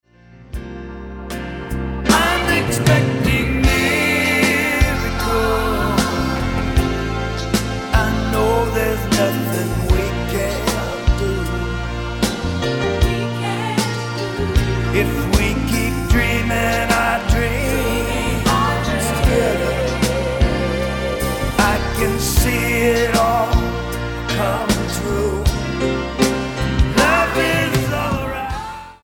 8872 Style: Blues Approac